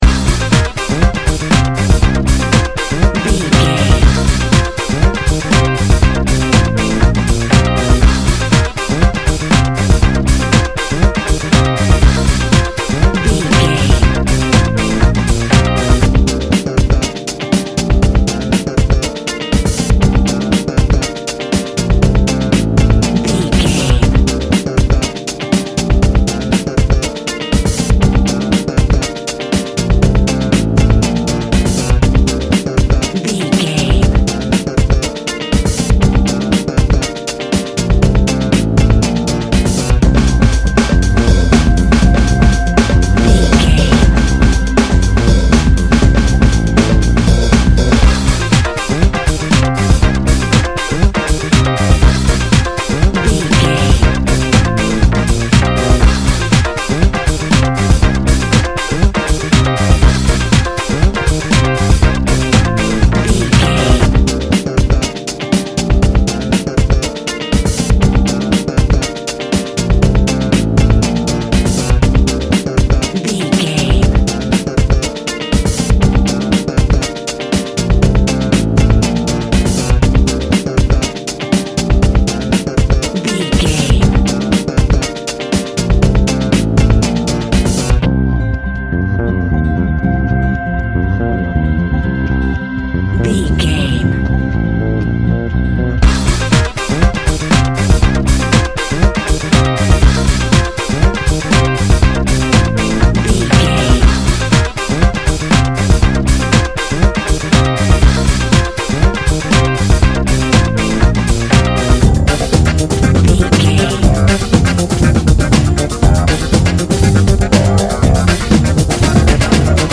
A Funky Hip Hop Song
Fast paced
Aeolian/Minor
Fast
aggressive
playful
smooth
groovy
energetic
funky
uplifting
bass guitar
electric guitar
organ
synthesiser
drum machine
drums
electric piano
strings